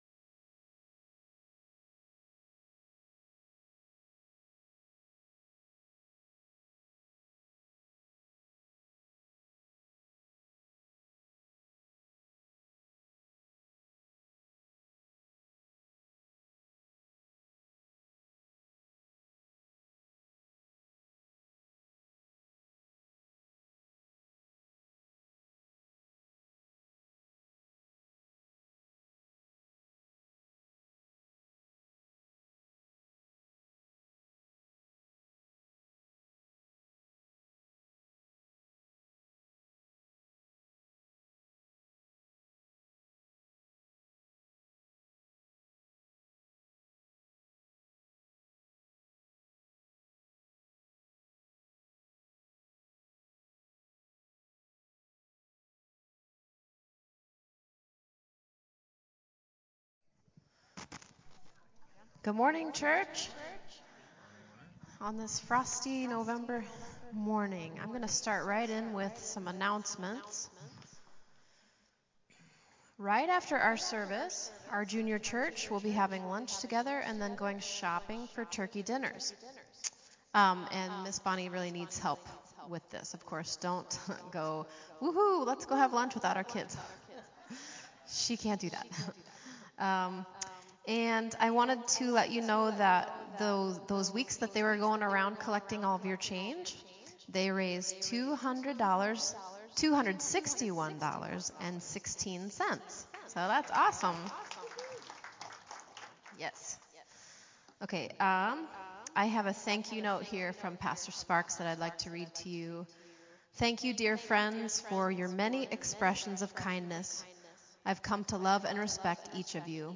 Praise Worship